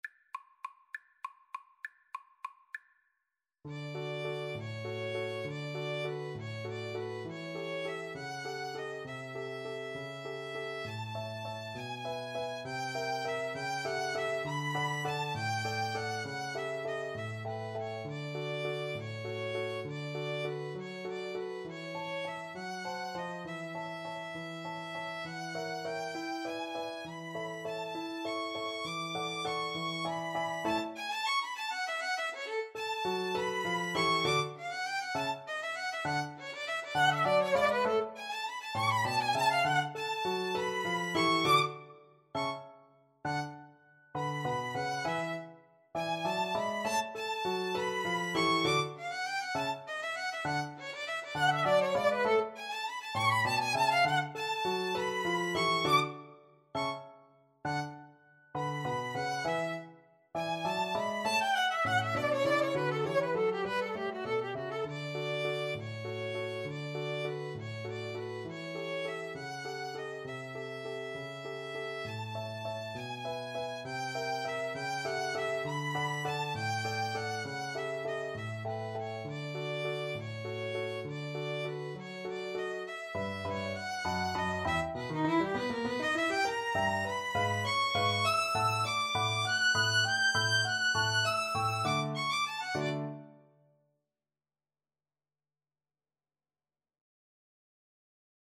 3/4 (View more 3/4 Music)
~=200 One in a bar
D major (Sounding Pitch) (View more D major Music for Violin-Viola Duet )
Violin-Viola Duet  (View more Intermediate Violin-Viola Duet Music)
Classical (View more Classical Violin-Viola Duet Music)